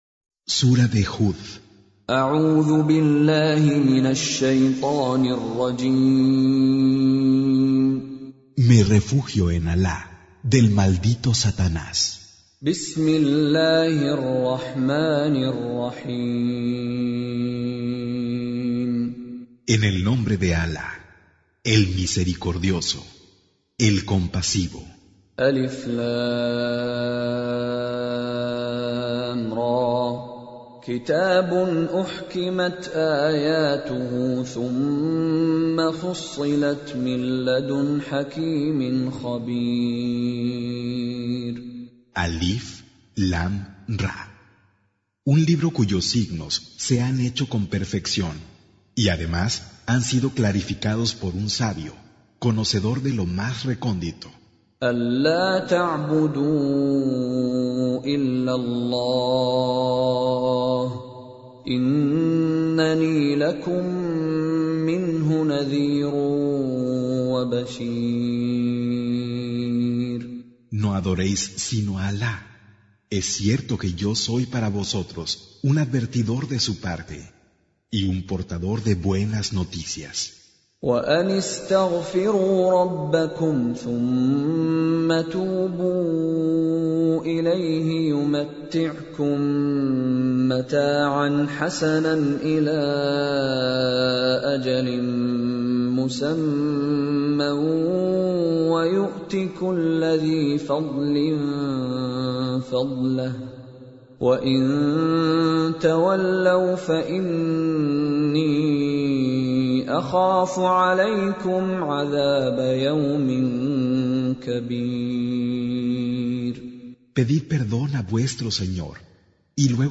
11. Surah H�d سورة هود Audio Quran Tarjuman Translation Recitation
Con Reciter Mishary Alafasi